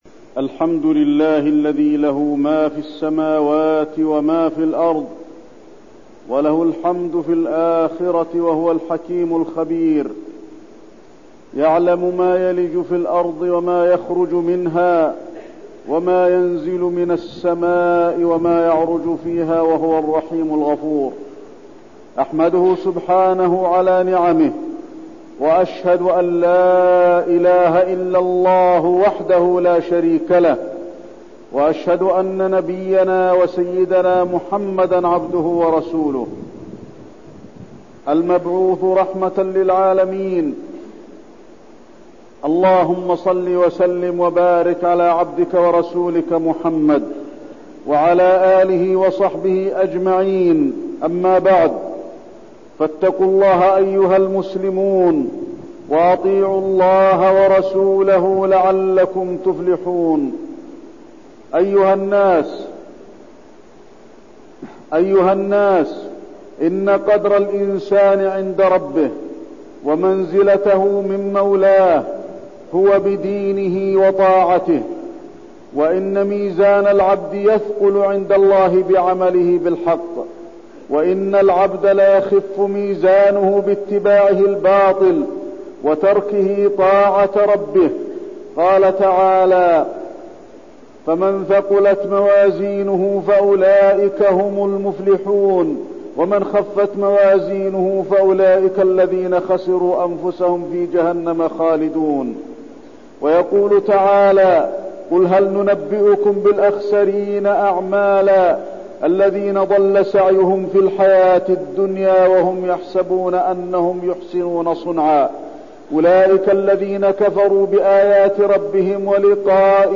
تاريخ النشر ١٦ شوال ١٤١٠ هـ المكان: المسجد النبوي الشيخ: فضيلة الشيخ د. علي بن عبدالرحمن الحذيفي فضيلة الشيخ د. علي بن عبدالرحمن الحذيفي الصلاة